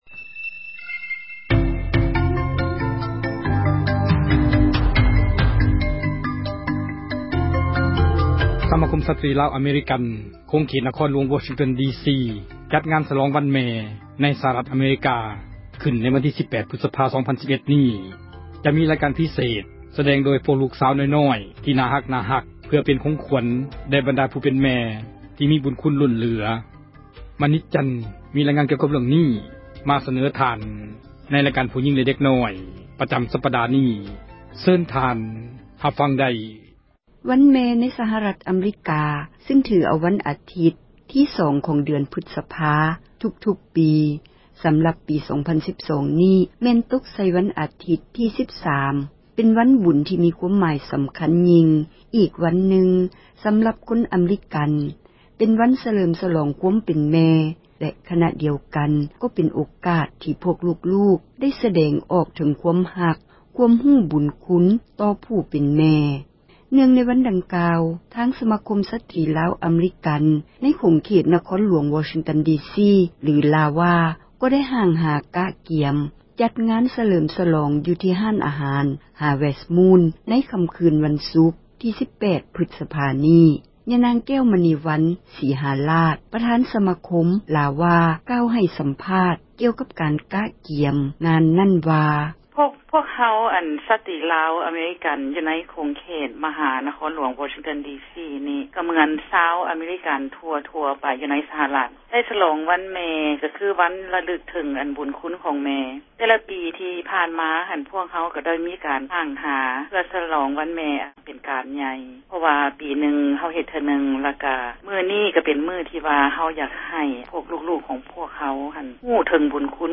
F-mother ການຟ້ອນນາຕສິນ ລາວເດີມ ເດັກນ້ອຍຜູ້ຍິງລາວ